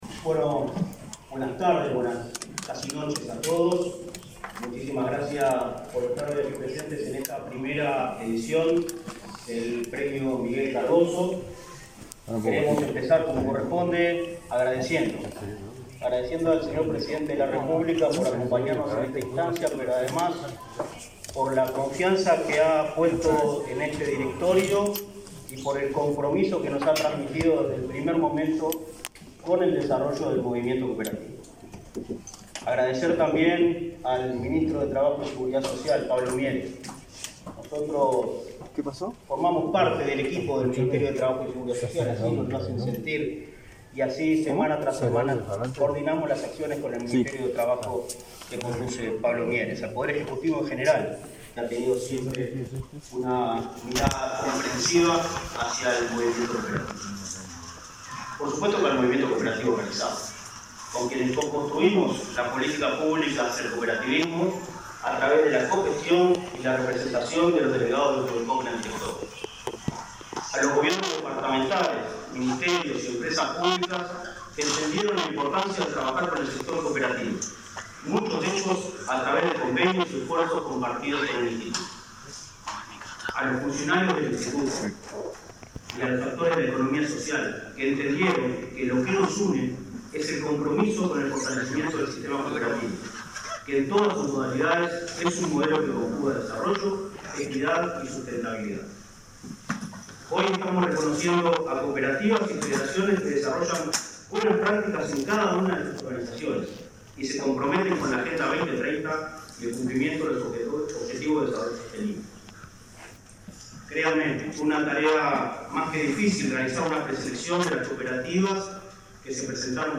Conferencia de prensa por la entrega del Premio Miguel Cardozo a cooperativas y organizaciones de la economía social y solidaria
Con la presencia del presidente de la República, Luis Lacalle Pou, se realizó, este 28 de octubre, el acto de entrega del Premio Miguel Cardozo,